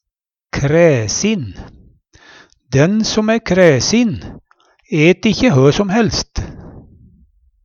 kræsin - Numedalsmål (en-US)